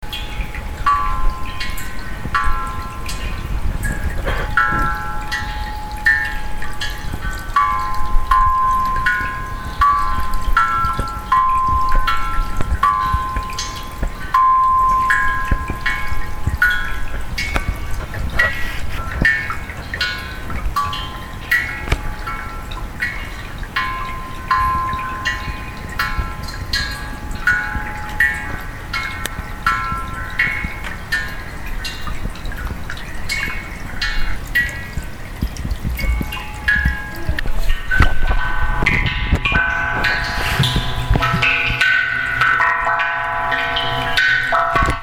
それは、真言宗のお経、理趣経（中曲）の讃嘆偈「善哉」のある部分が二部合唱で唱えられたものでした。
理趣経の讃嘆偈（二部合唱）